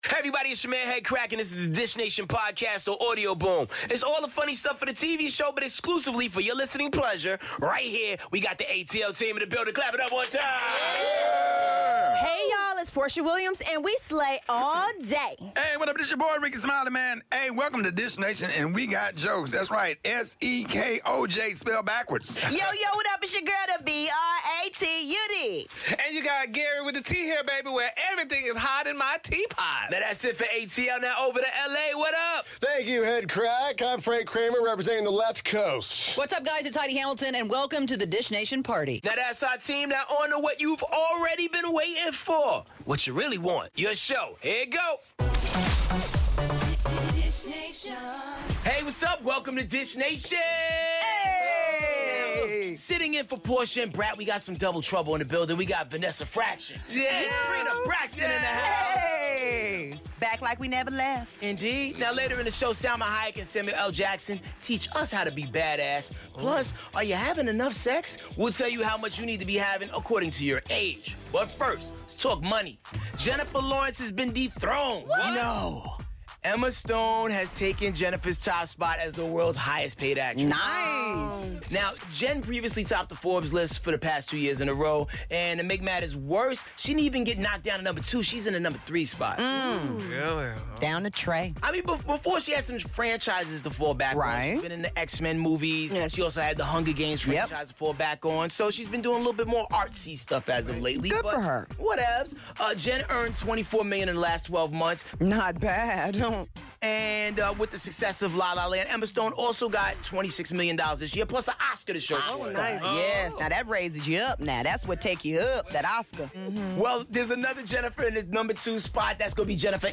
We dish with Samuel L. Jackson and Salma Hayek about their new movie 'The Hitman's Bodyguard' plus all the latest with Jennifer Lopez, John Legend, Selena Gomez and more.